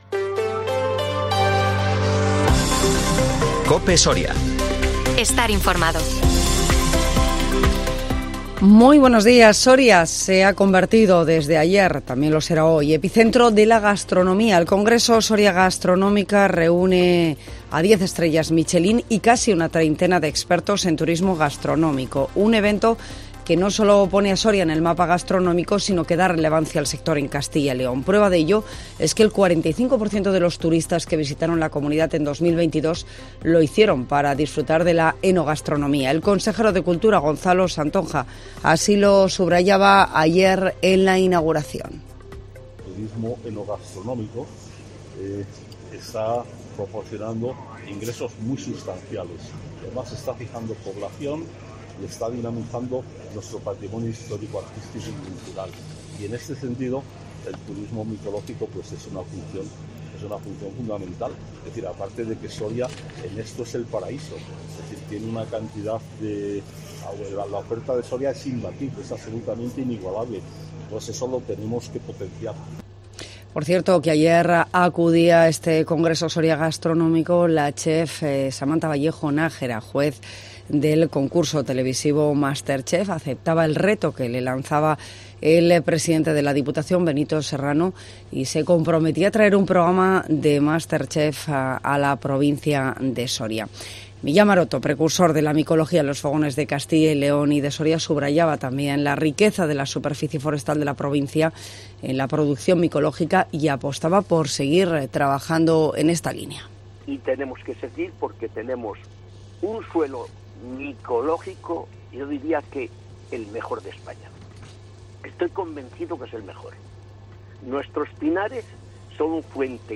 AUDIO: Las noticias en COPE Soria